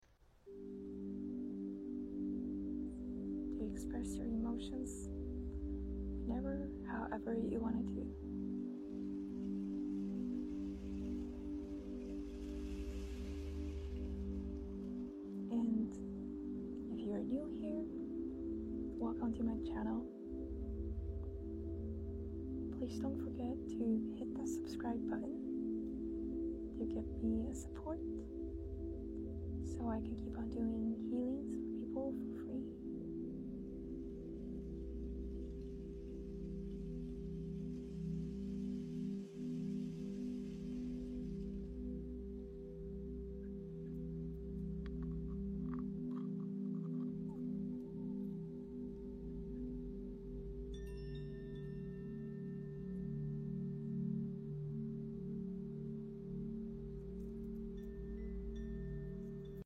Reiki ASMR